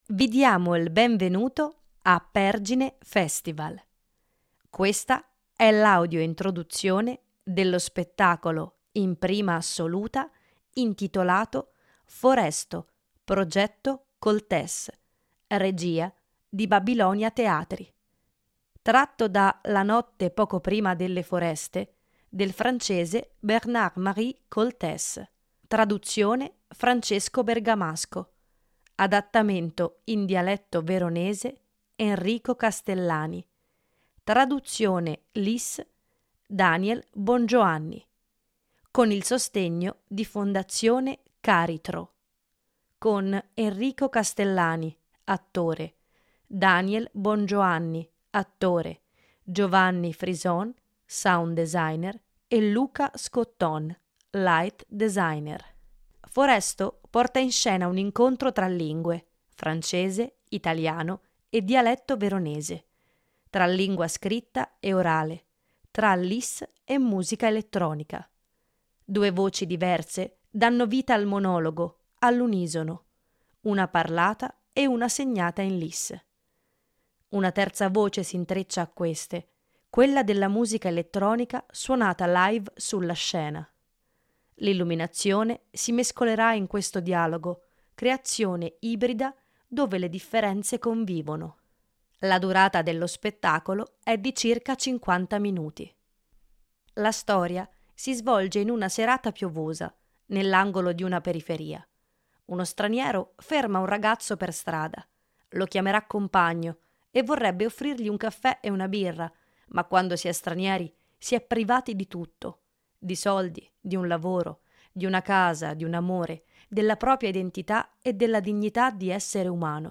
Audio introduzione: